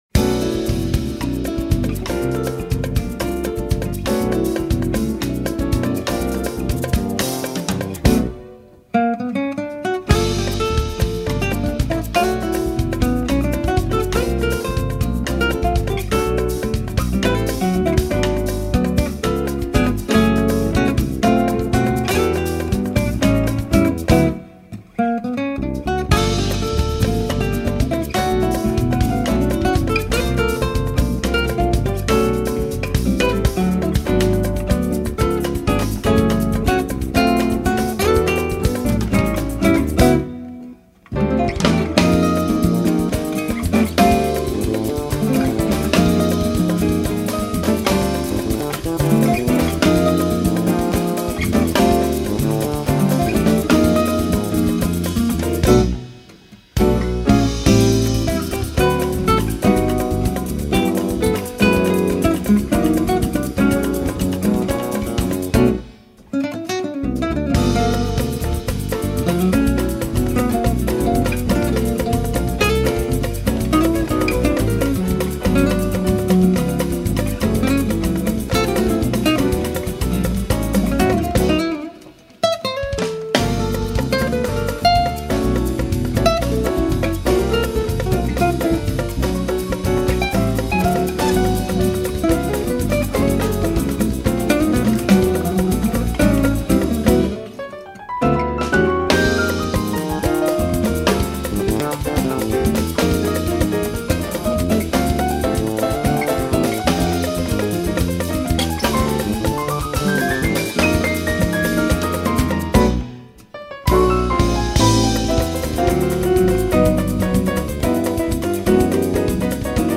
02:37:00   Instrumental